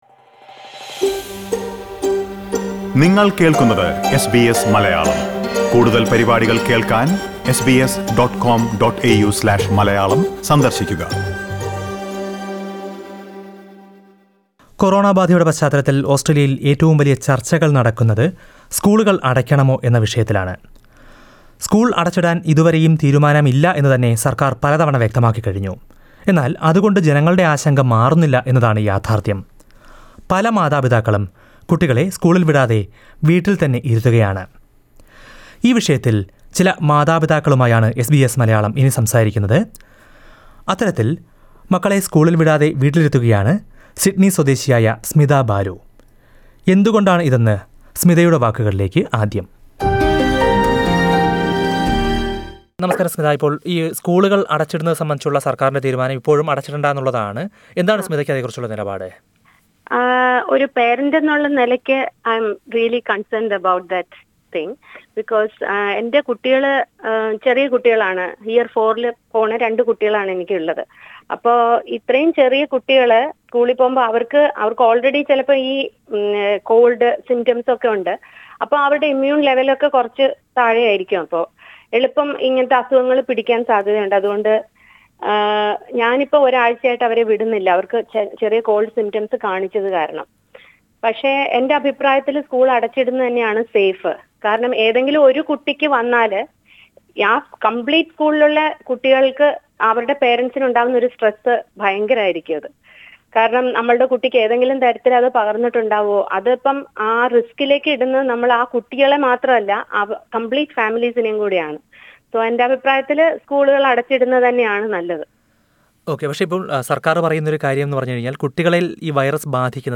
ഈ വ്യത്യസ്ത നിലപാടുകളുടെ കാരണങ്ങള്‍ ഓസ്‌ട്രേലിയന്‍ മലയാളികള്‍ വിശദീകരിക്കുന്നത് ഇവിടെ കേള്‍ക്കാം.